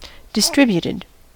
distributed: Wikimedia Commons US English Pronunciations
En-us-distributed.WAV